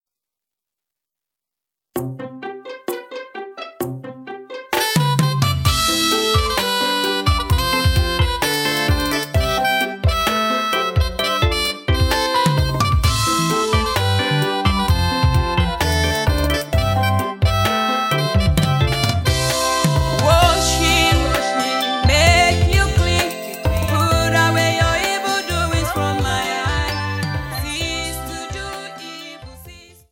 bright songs